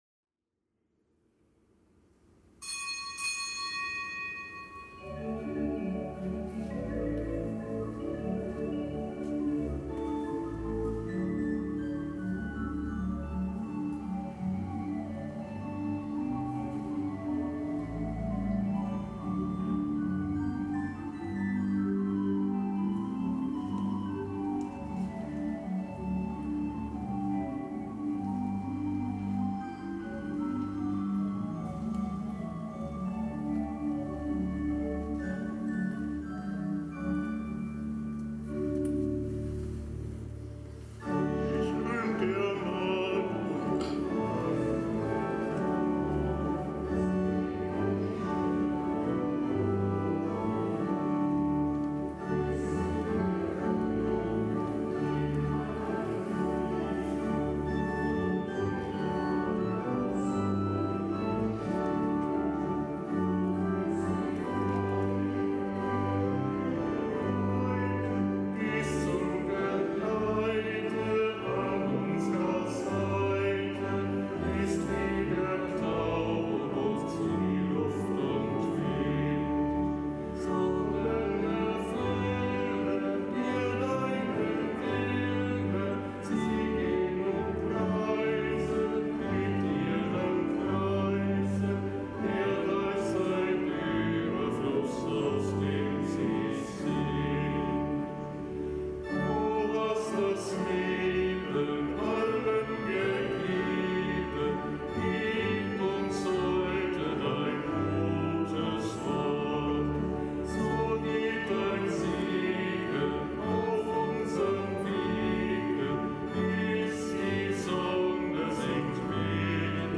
Kapitelsmesse am Mittwoch der neunzehnten Woche im Jahreskreis
Kapitelsmesse aus dem Kölner Dom am Mittwoch der neunzehnten Woche im Jahreskreis.